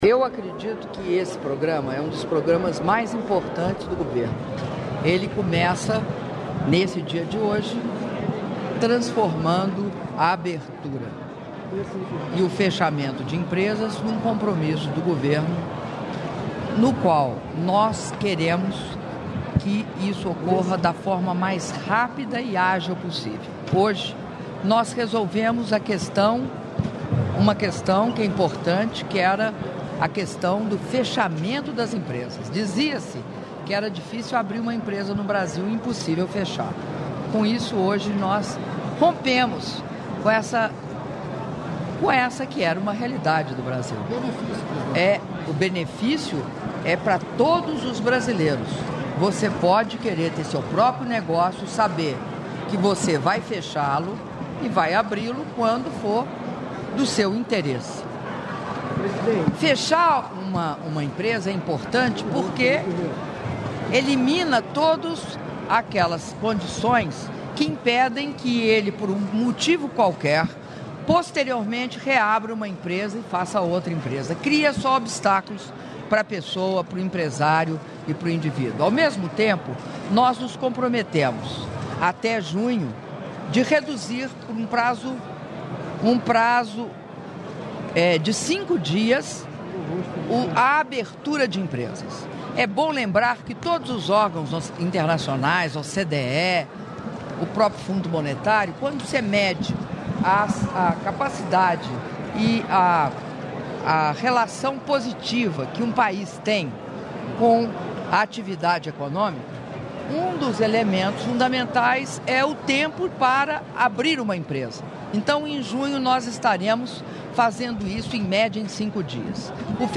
Áudio da entrevista coletiva concedida pela Presidenta da República, Dilma Rousseff, após cerimônia de Lançamento do Programa Bem Mais Simples Brasil e do Sistema Nacional de Baixa Integrada de Empresas - Brasília/DF (4min54s)